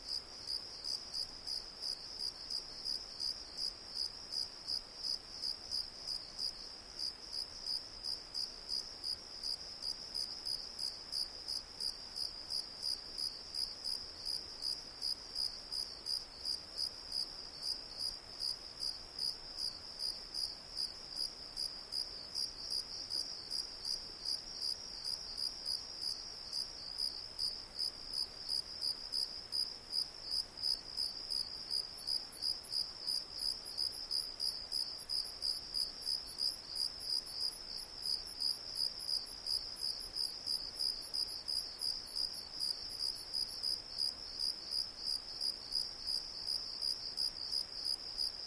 crickets_6.ogg